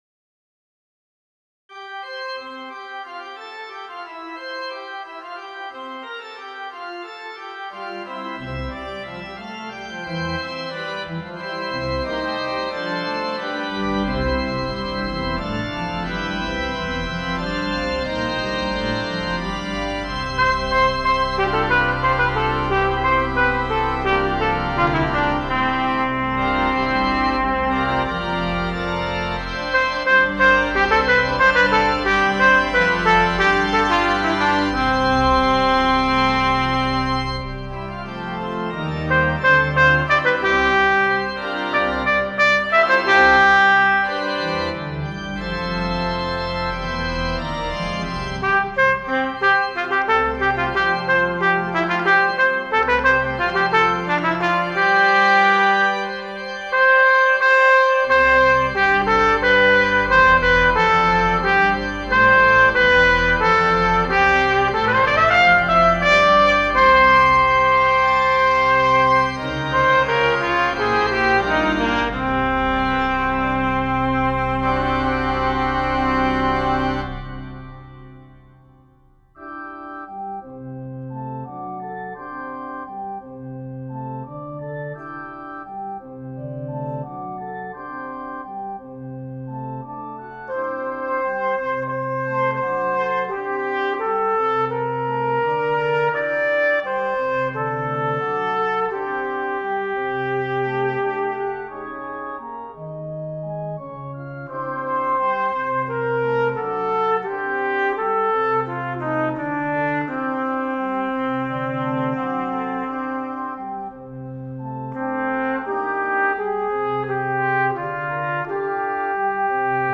Voicing: Brass Solo w/